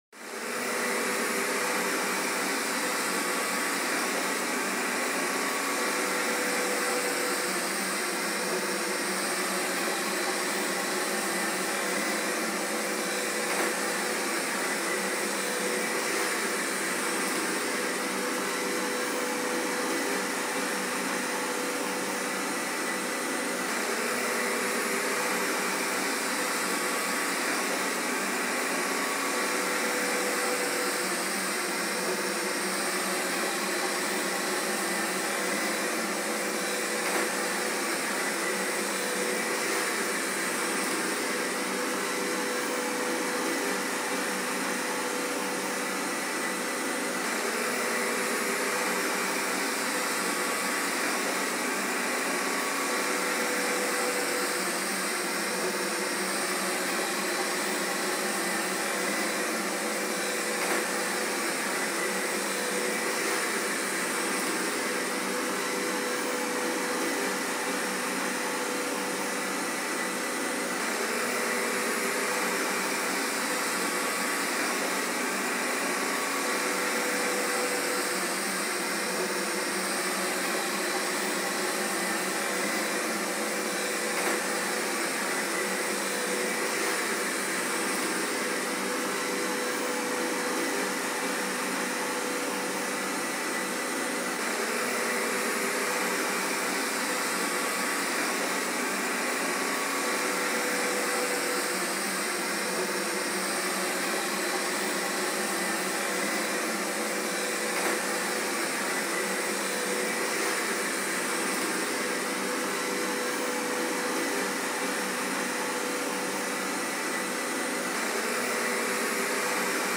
Звуки робота-пылесоса
Сняли насадку с водой для мокрой уборки